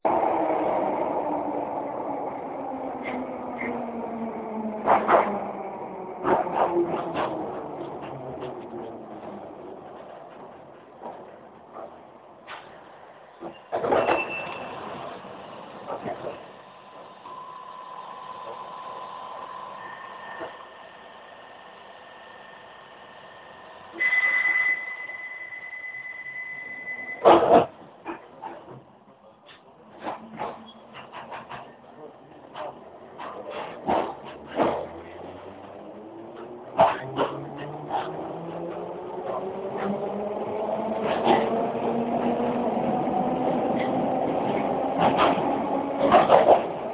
Bakerloo 4号线进站和出站
描述：用我的诺基亚N70录制的一个粗糙的现场录音。
大量的背景噪音。
Tag: 场记录 伦敦地下 车站 列车